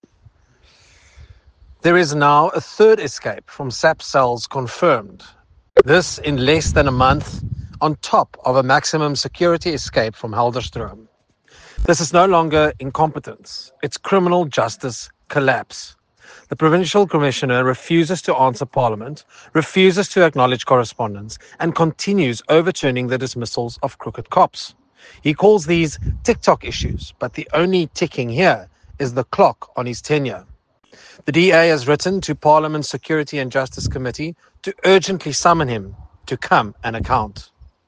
English soundbite by Nicholas Gotsell MP.